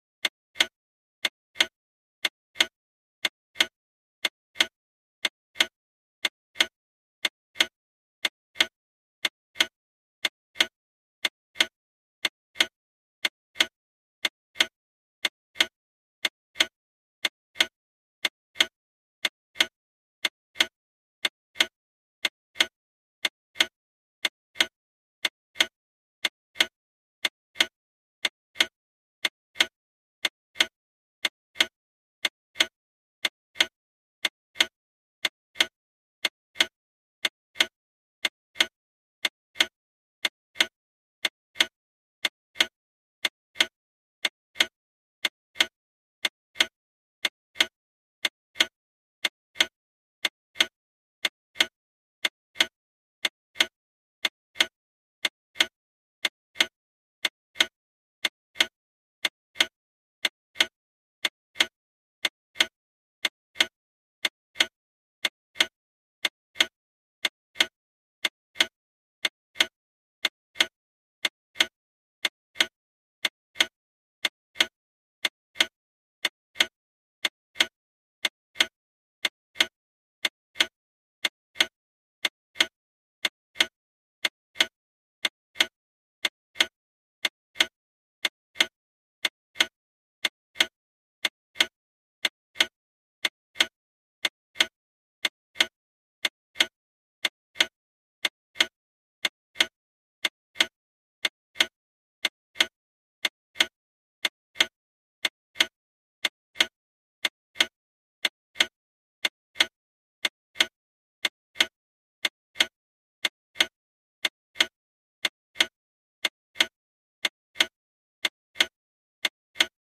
Antique Wind-up Wall Clock Ticks.